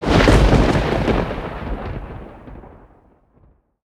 Звук резкий удар грозы.